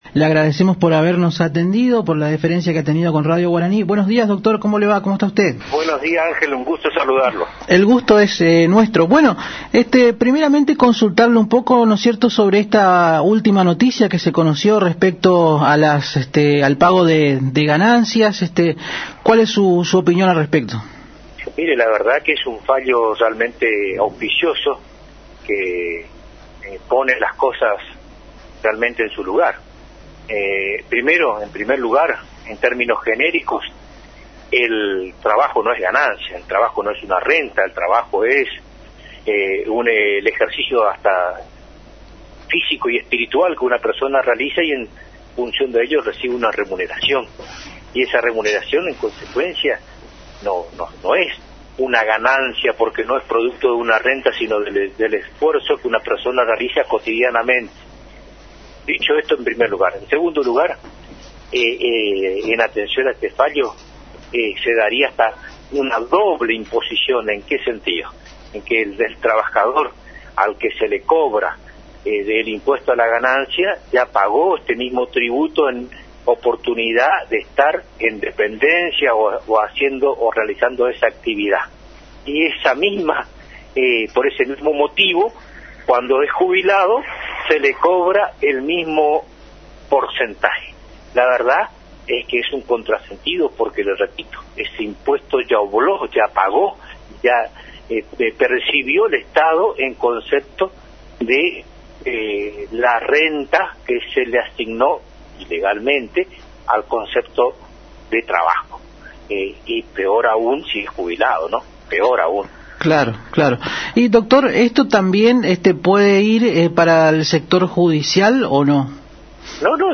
(Audio) El ex Fiscal de Estado Armando Aquino Britos en diálogo con la AM 970 Radio Guarani analizó el fallo judicial por el cual se declaró la inconstitucionalidad del cobro de ganancias para los jubilados que se conoció días atrás.